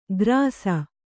♪ grāsa